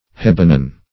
hebenon - definition of hebenon - synonyms, pronunciation, spelling from Free Dictionary Search Result for " hebenon" : The Collaborative International Dictionary of English v.0.48: Hebenon \Heb"e*non\, n. See Henbane .